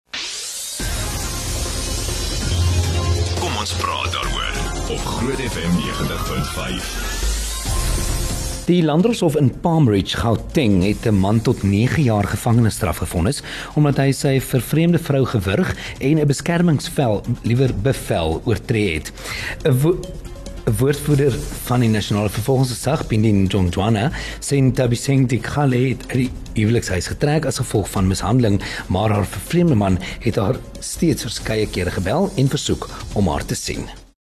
sukkel om sy lag te sluk in Kom Ons Praat Daaroor se nuusbulletin.